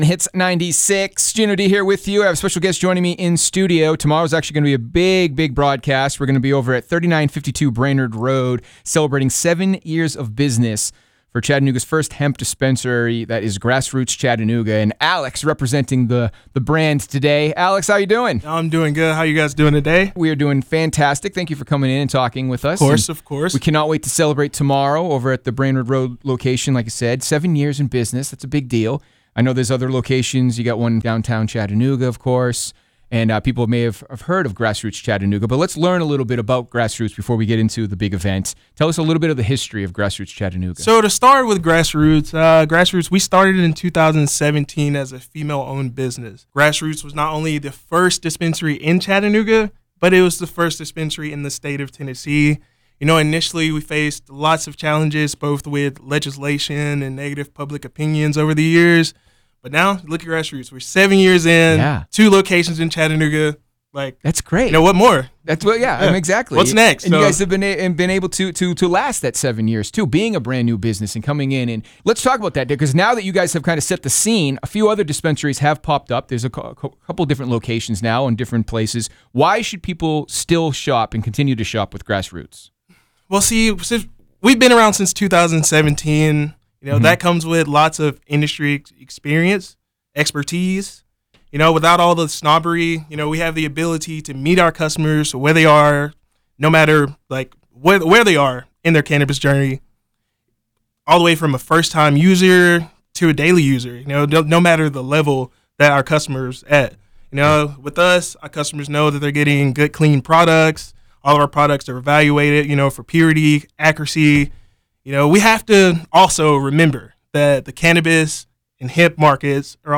Full-Interview-Grass-Roots.wav